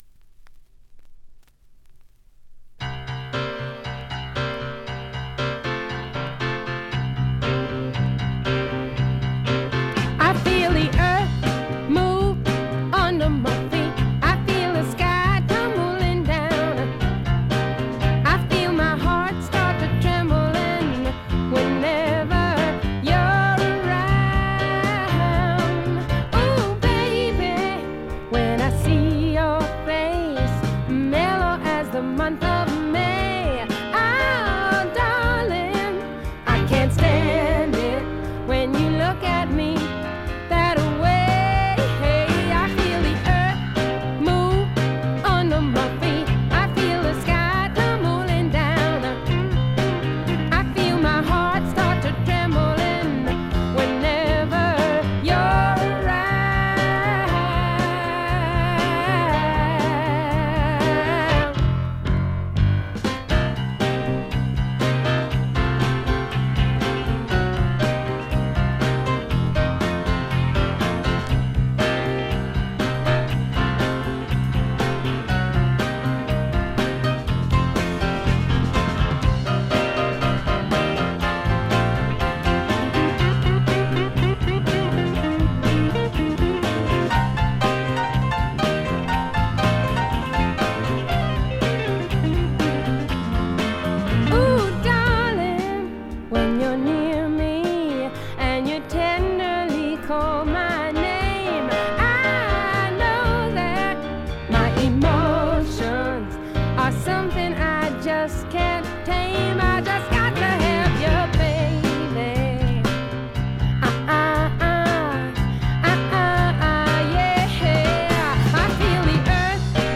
静音部で軽微なバックグラウンドノイズ、チリプチ少々。
女性シンガーソングライター基本中の基本。
試聴曲は現品からの取り込み音源です。